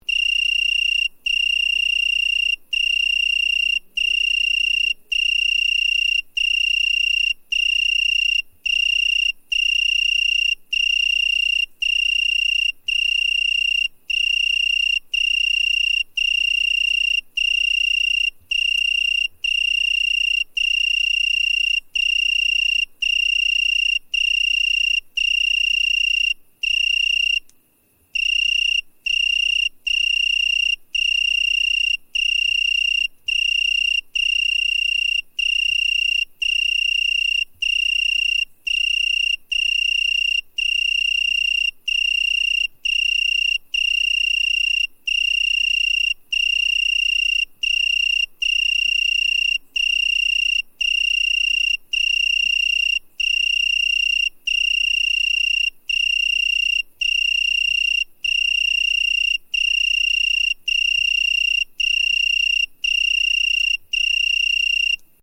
Weinhähnchen
Wenn es dämmert, beginnt das Weinhähnchen zu singen. Es ist die lauteste der einheimischen Heuschreckenarten.
Hören Sie sich hier den Gesang des Weinhähnchens an.
Weinhaehnchen.mp3